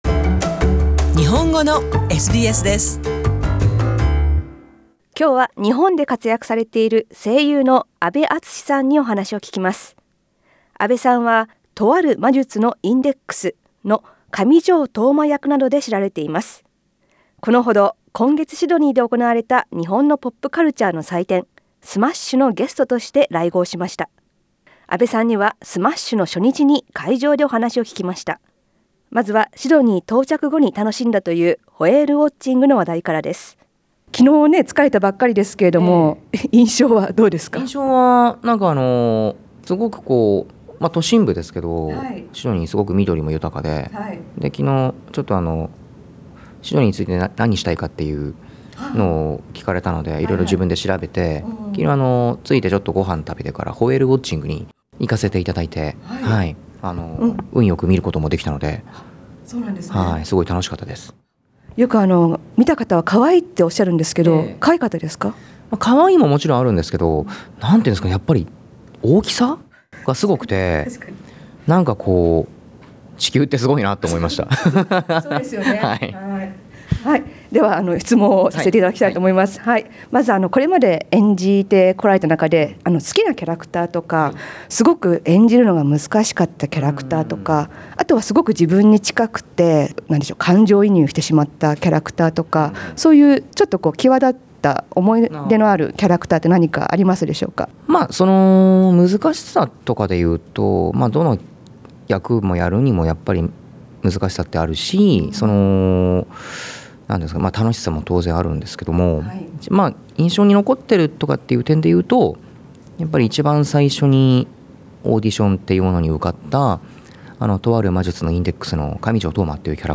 声優業界でも特に自分は仕事を楽しんでおり、「好きなことをやっているという自負」があるという阿部さんに、シドニーのことやこれから演じてみたい役など、お話を聞きました。